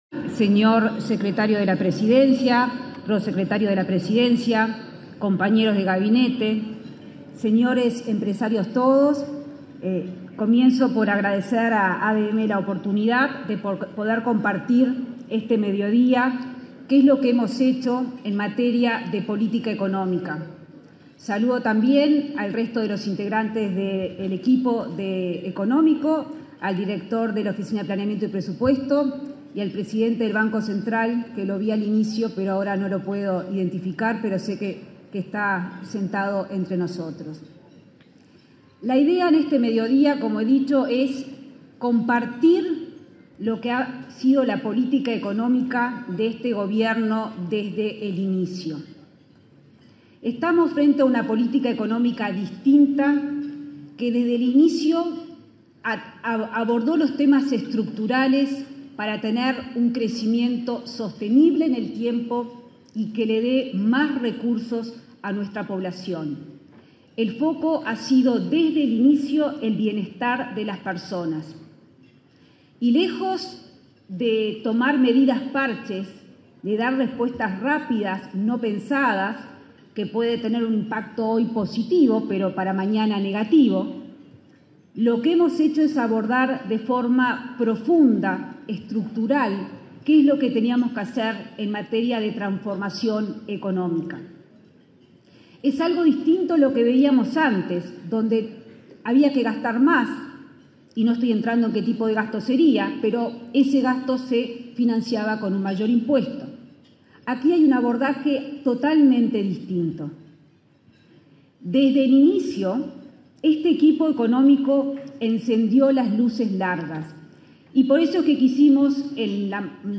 Palabras de la ministra de Economía y Finanzas, Azucena Arbeleche
En el marco del almuerzo de trabajo organizado por la Asociación de Dirigentes de Marketing del Uruguay (ADM), este 9 de mayo, se expresó la ministra